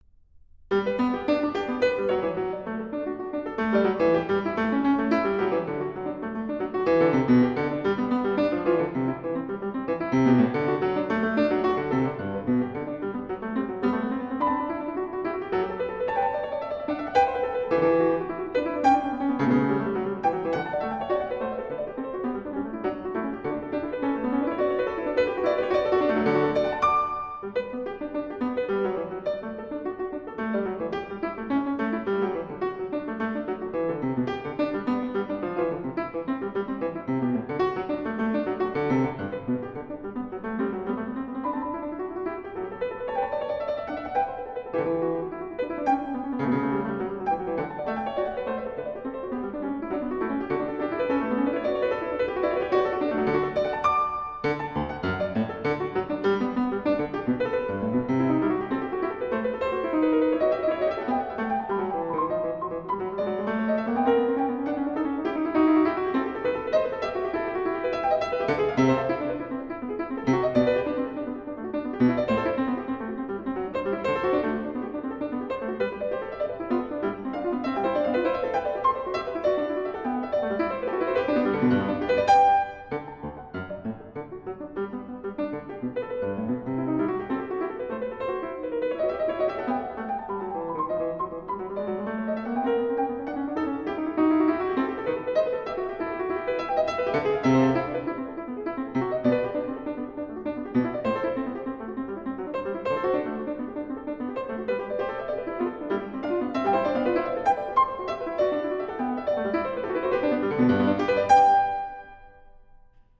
演奏的是现代的音乐会钢琴，但听起来颇有古风。
她演奏的萨拉班德、吉格舞曲，触键非常优雅。在咏叹调中，则始终充满温情。
主题和对位能够从容展开。
介于古乐与现代演绎之间，但又集两者的优点子一身。